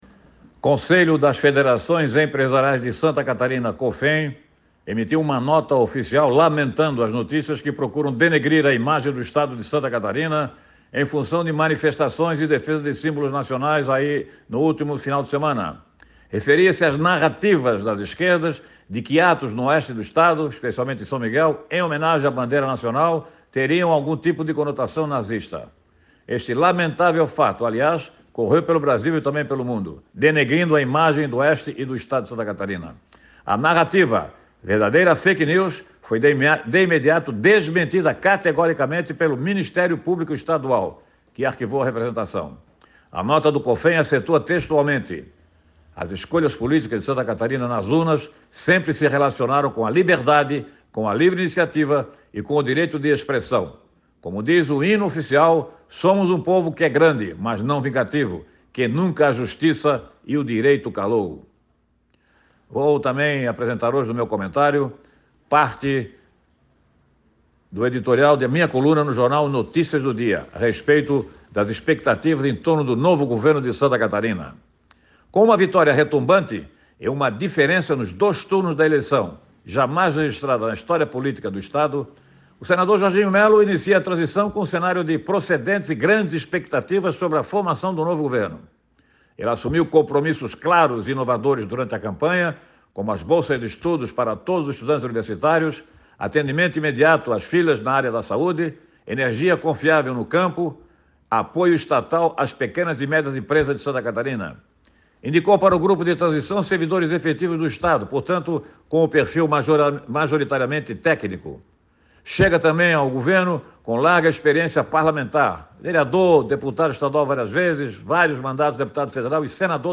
Jornalista destaca a nota emitida pela Cofen em defesa de Santa Catarina e a futura gestão do governo do Estado